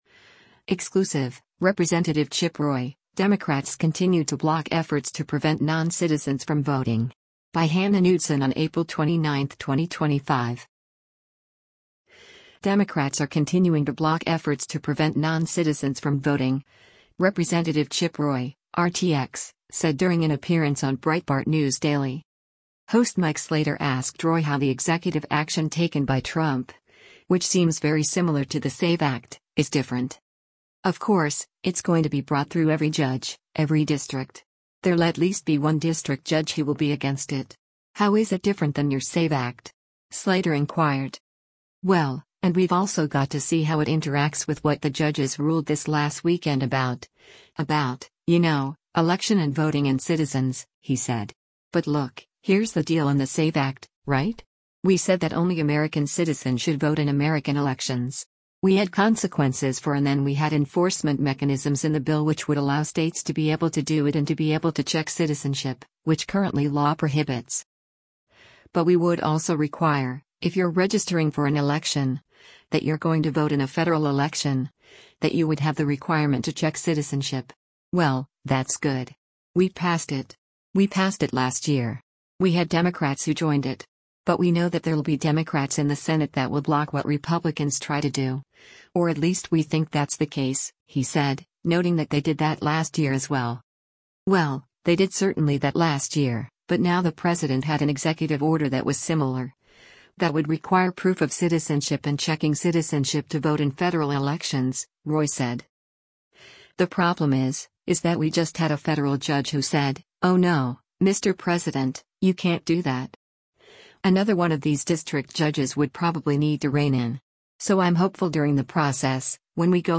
Democrats are continuing to block efforts to prevent non-citizens from voting, Rep. Chip Roy (R-TX) said during an appearance on Breitbart News Daily.